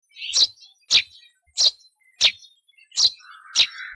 Moineau friquet
Passer montanus
friquet.mp3